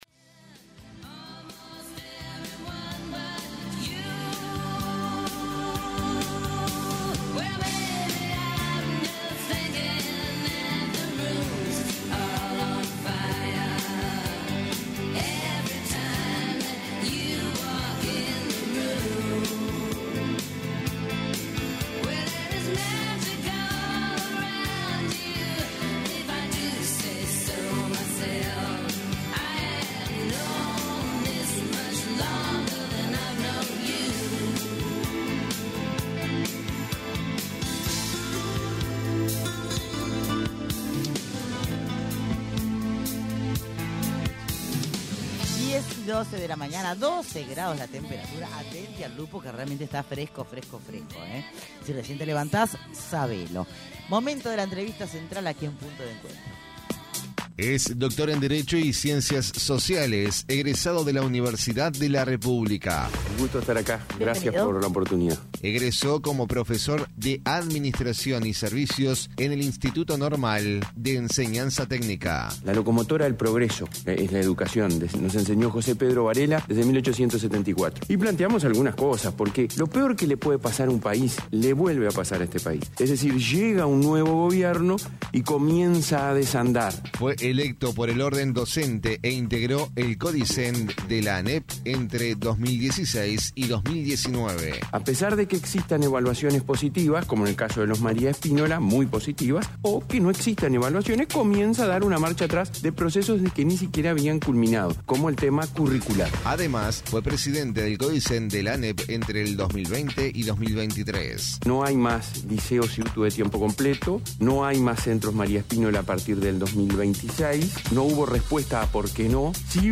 ENTREVISTA: ROBERT SILVA